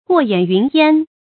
過眼云煙 注音： ㄍㄨㄛˋ ㄧㄢˇ ㄧㄨㄣˊ ㄧㄢ 讀音讀法： 意思解釋： 從眼前飄過的云煙。